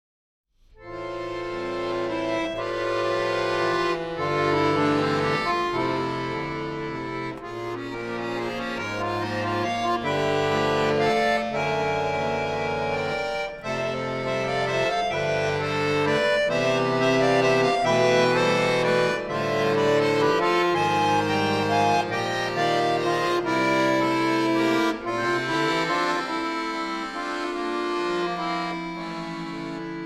for bayan solo
in the style of Russian songs